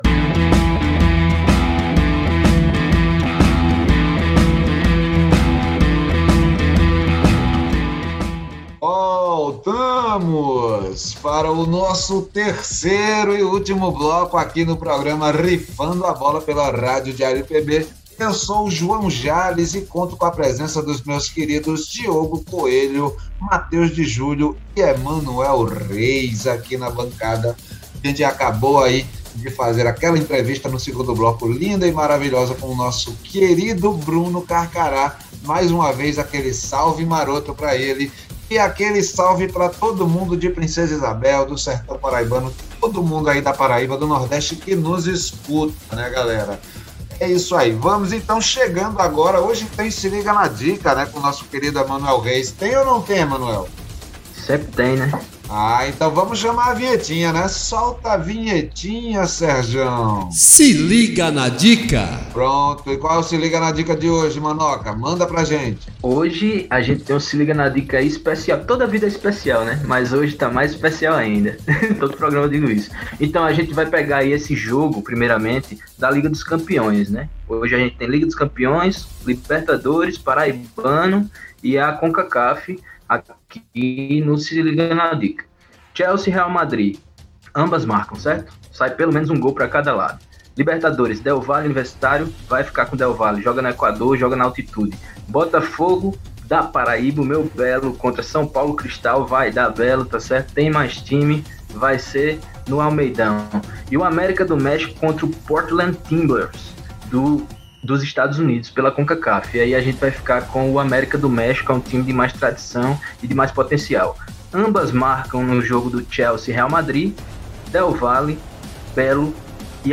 Numa entrevista concedida por aplicativos de mensagem, nosso craque bateu um papo descontraído com a bancada do programa.